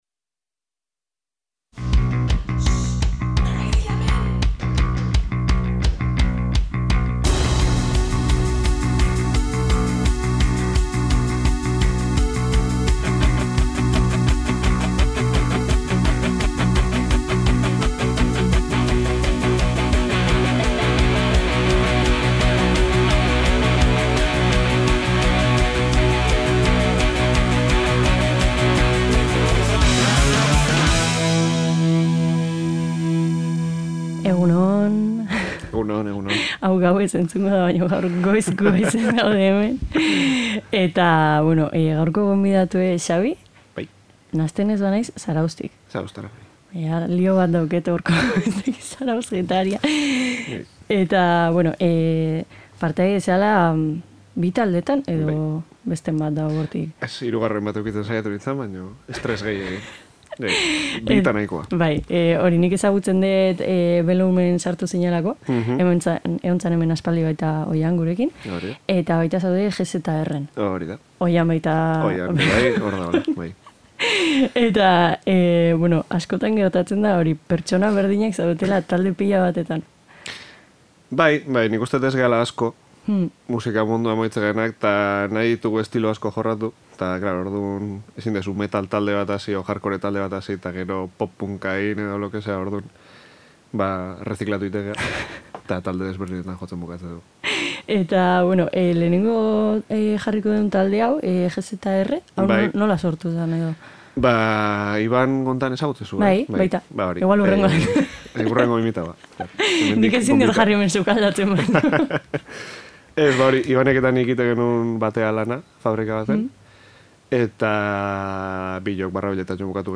Saio dinamiko honetan hainbat talde aipatu eta entzun ditugu, Estatu Batuetatik pasata, baina Urola Kosta ahaztu gabe, noski. Getariako Graka kolektiboa agertu da hizketaldian, eta beste hainbat zuzenekoen kontuak baita ere.